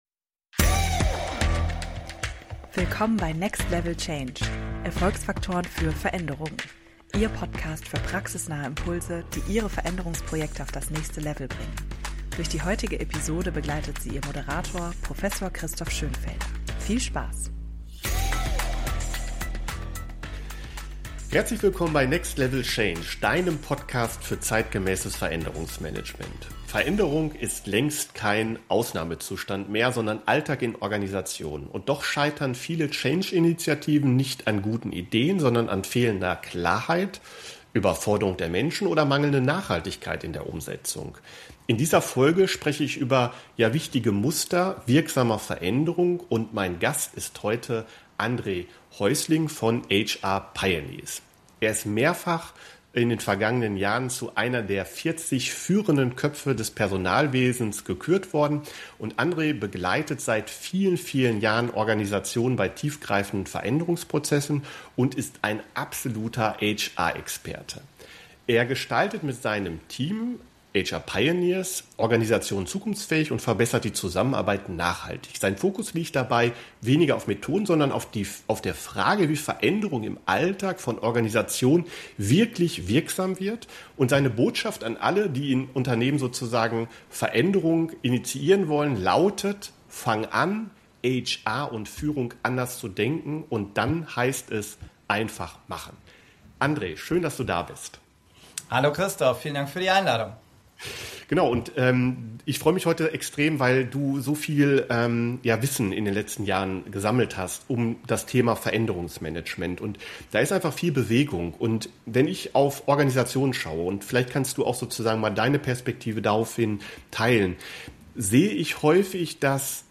– Tauchen Sie ein in ein Gespräch voller Praxis-Insights und neuer Perspektiven auf die Welt des Wandels.